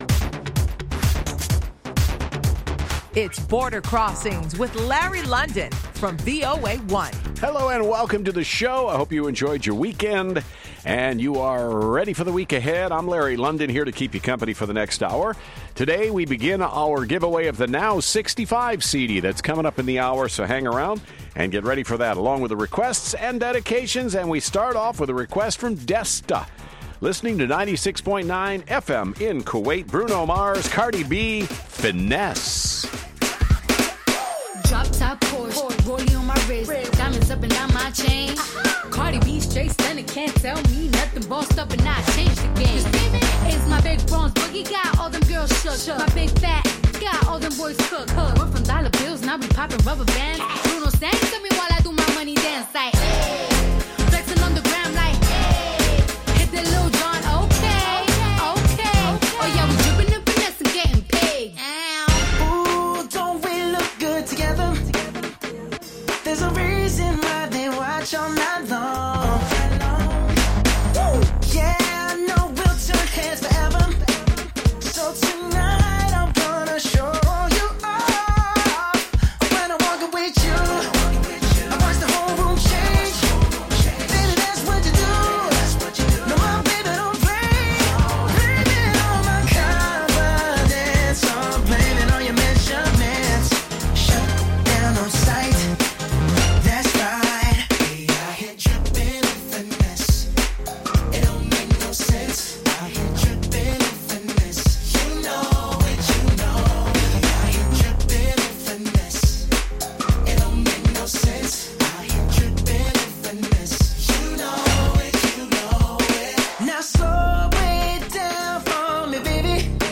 live worldwide international music request show